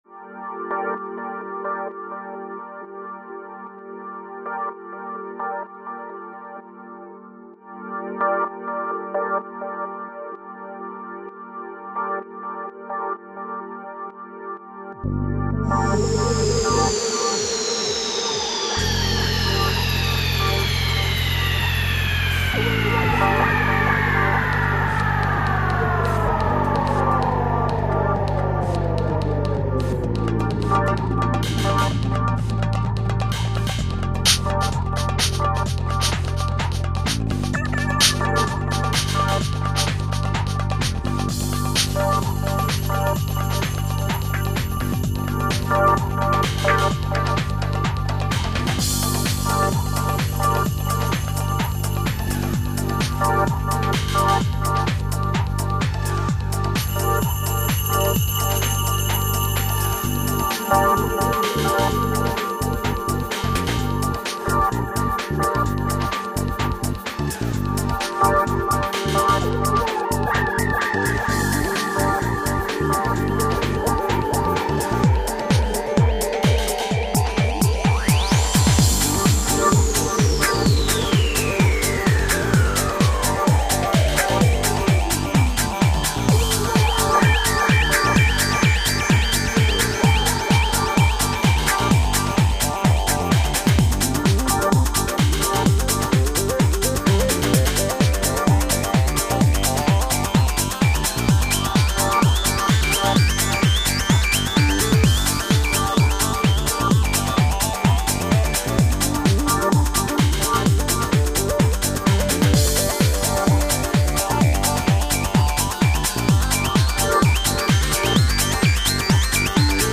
dance/electronic
House
Breaks & beats